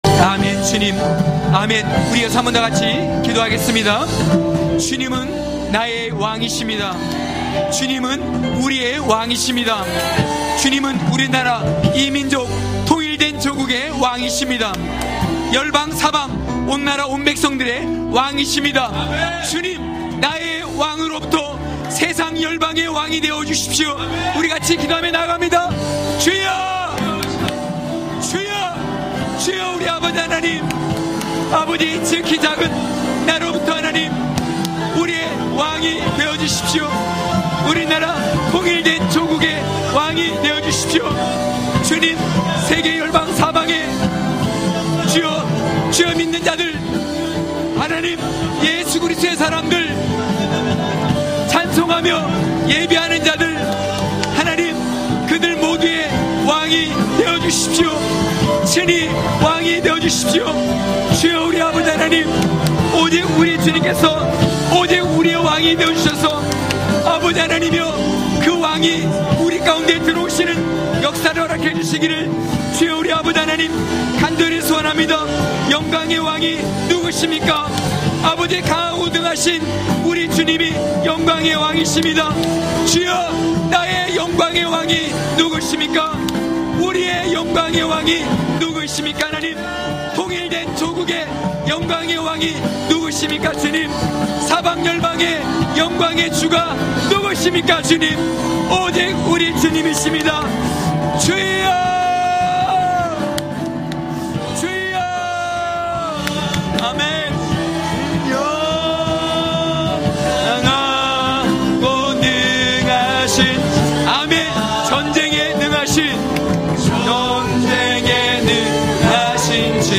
강해설교 - 20.중심을 지킨 느헤미야로...(느12장27~31,37절).mp3